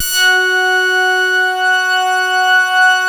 BAND PASS .7.wav